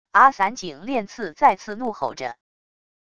阿散井恋次再次怒吼着wav音频